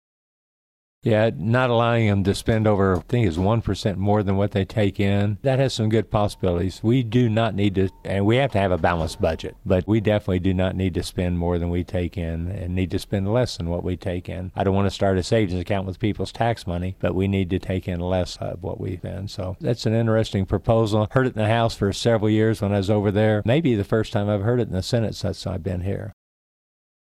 The following cuts are taken from the interview above with Sen. Cunningham, for the week of April 7, 2014.